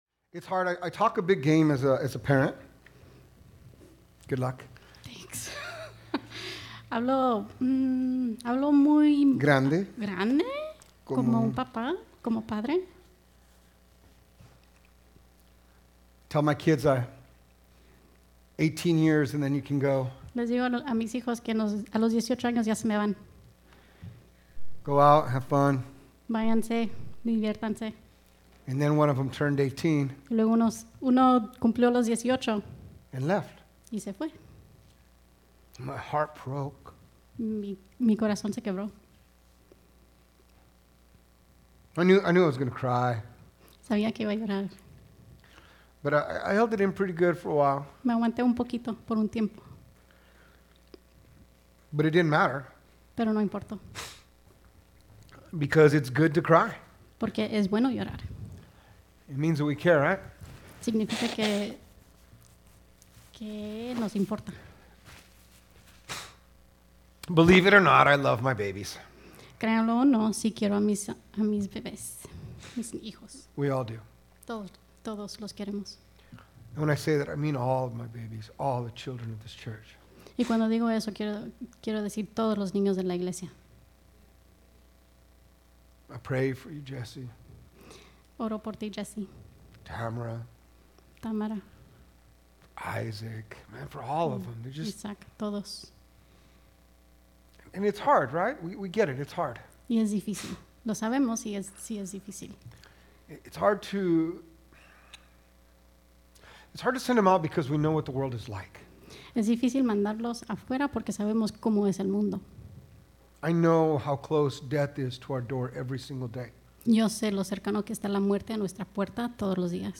Sermons | Mercy Springs Church of the Nazarene